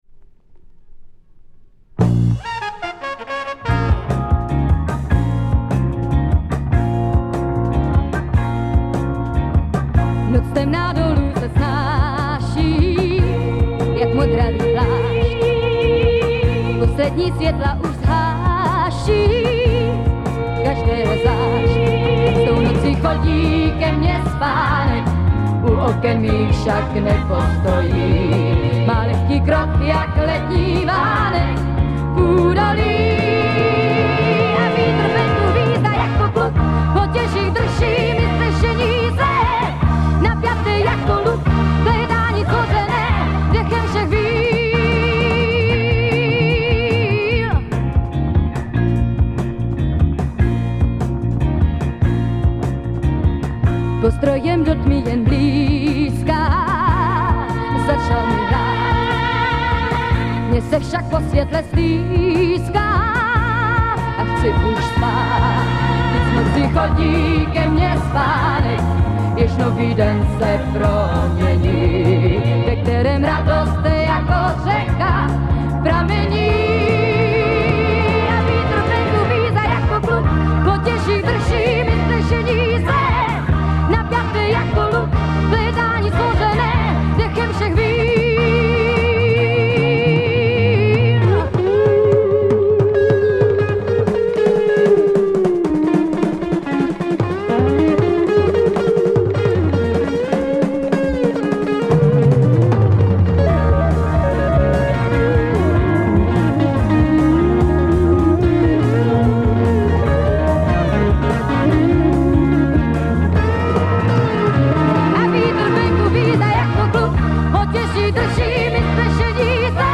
Czech Female psych dancer
super powerful soulish vocal